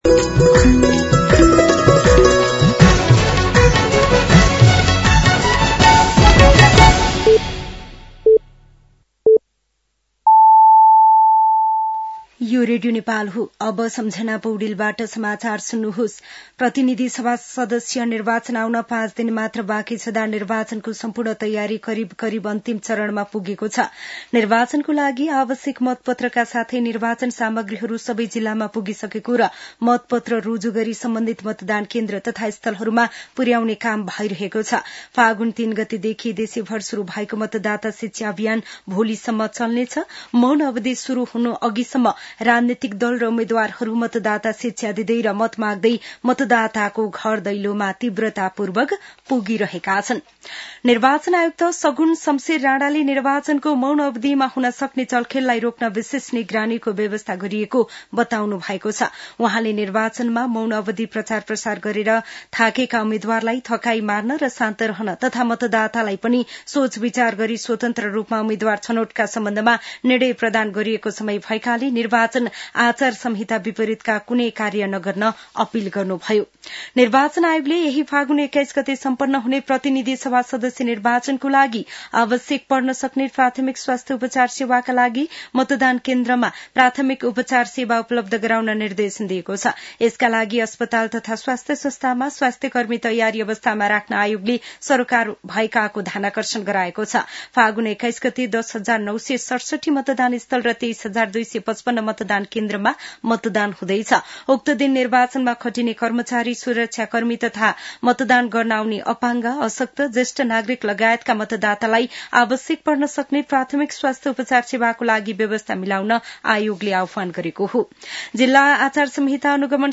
साँझ ५ बजेको नेपाली समाचार : १६ फागुन , २०८२
5.-pm-nepali-news-1-12.mp3